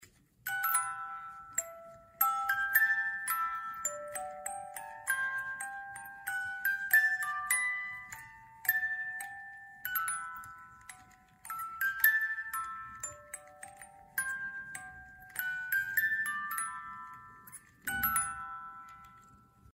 Шкатулка